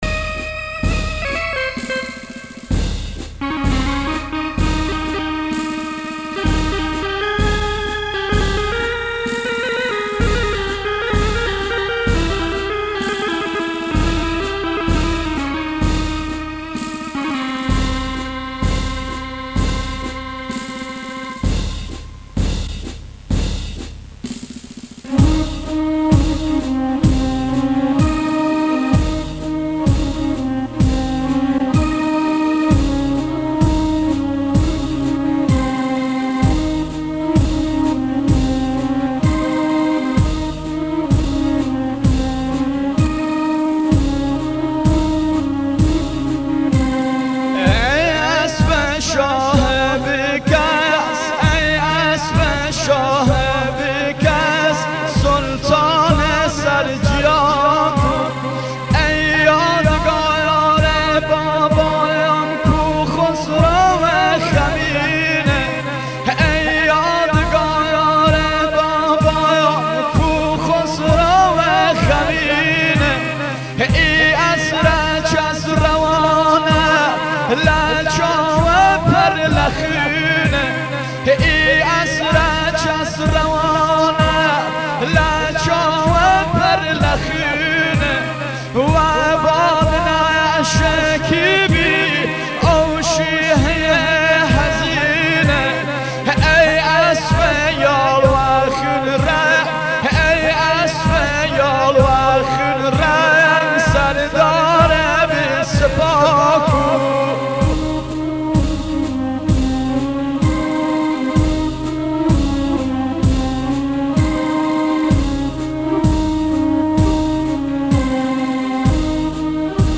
• دانلود نوحه و مداحی